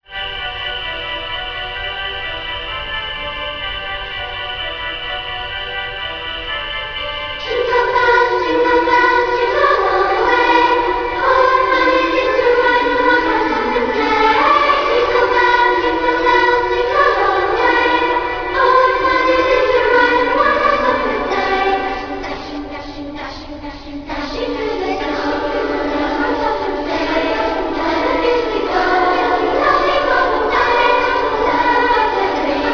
Bells
organ.